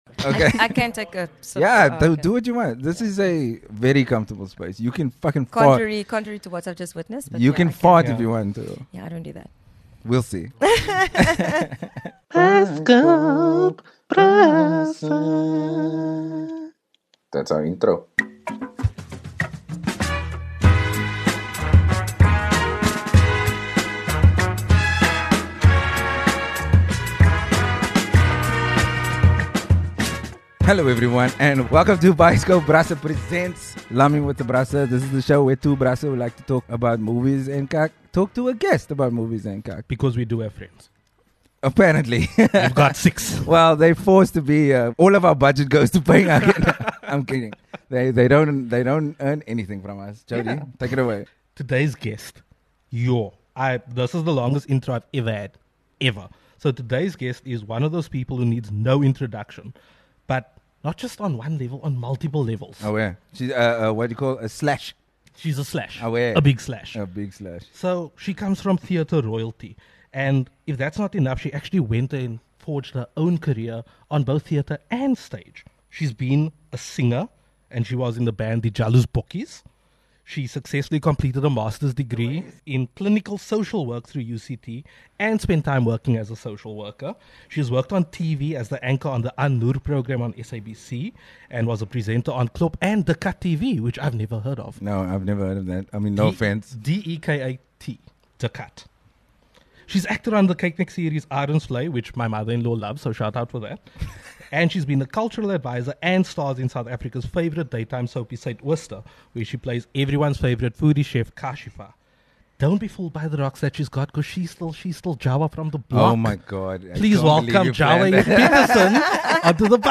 This episode is full of laughing and even some singing (thankfully not from us) and we can't wait for you to hear it.